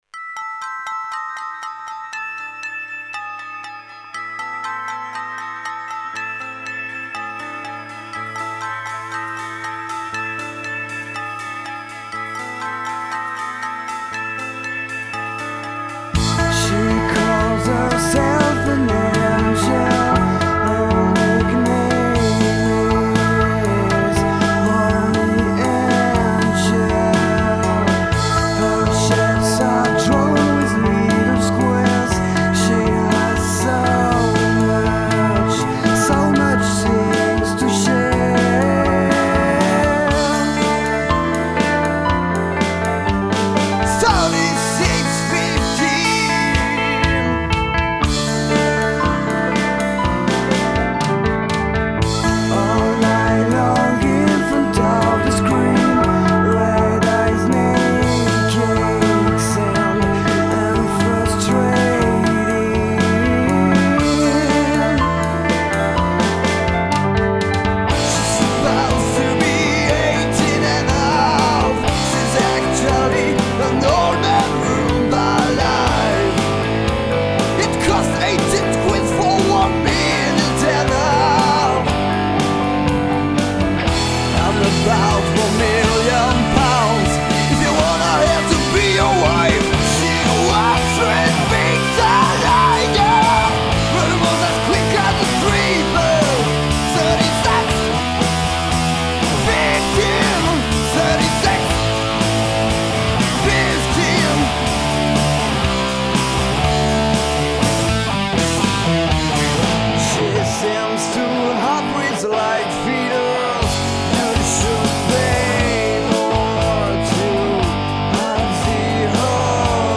Asparagus Ginger Mint divers Lyon 2007 - 2011 Compos et reprises rock Grâce à eux j'ai franchi un cap.